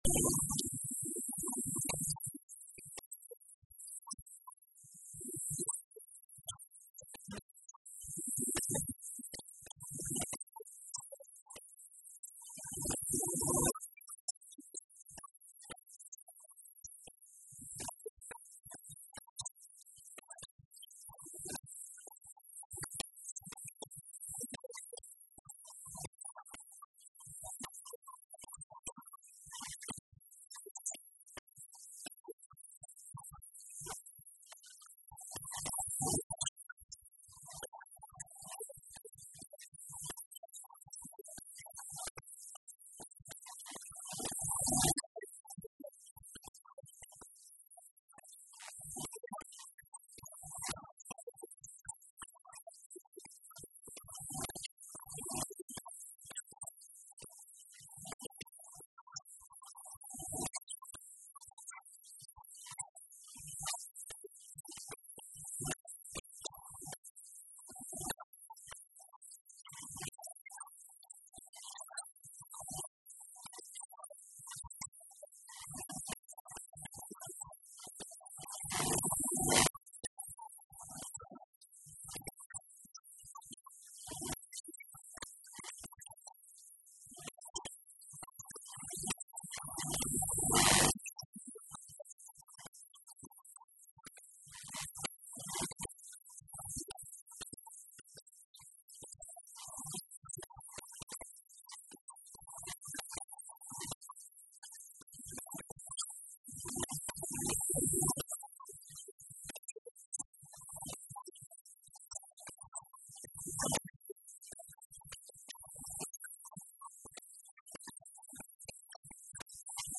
O Secretário Regional dos Recursos Naturais afirmou hoje, na Assembleia Legislativa, na Horta, que o novo regime do Fundo de Compensação Salarial dos Profissionais da Pesca dos Açores (FUNDOPESCA) visa dotar este instrumento de “maior transparência, previsibilidade e justiça social”.